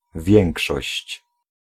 Ääntäminen
Ääntäminen US US : IPA : /məˈdʒɑrɪti/ IPA : /məˈdʒɔrɪti/ Haettu sana löytyi näillä lähdekielillä: englanti Käännös Ääninäyte Substantiivit 1. większość {f} Määritelmät Substantiivit More than half (50%) of some group.